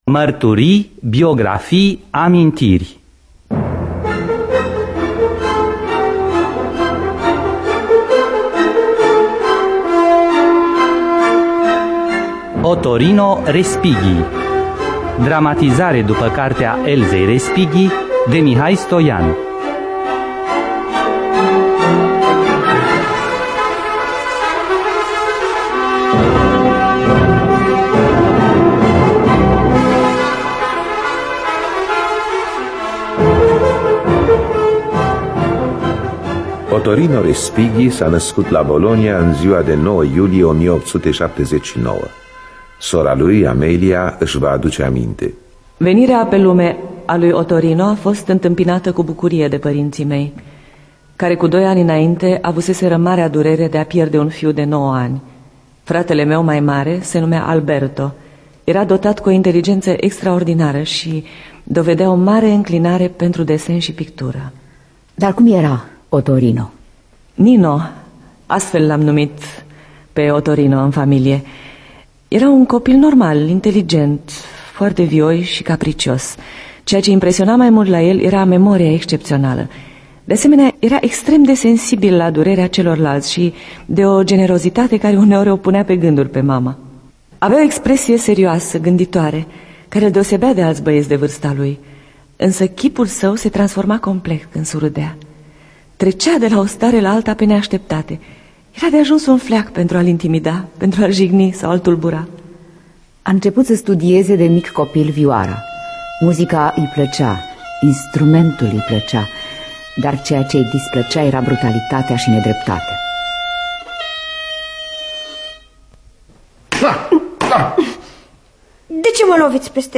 Dramatizarea radiofonică de Mihai Stoian după cartea Elsei Respighi.